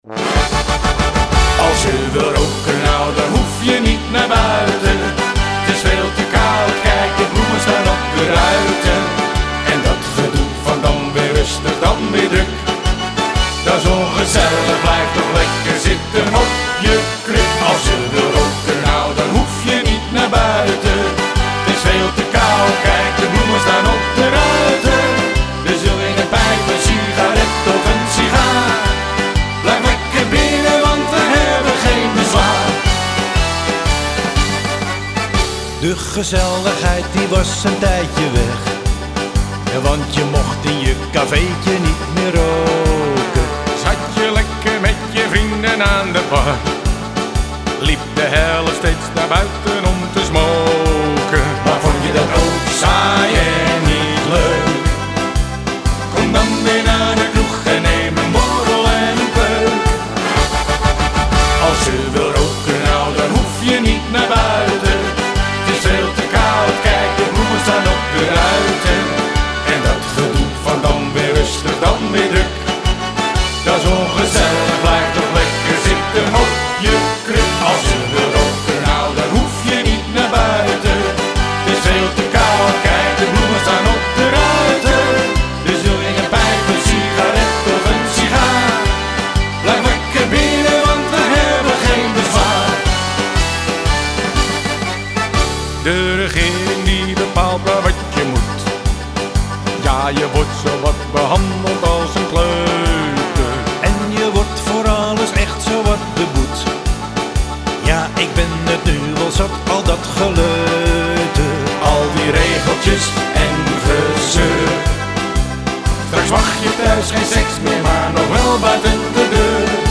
feestnummer
carnavalslied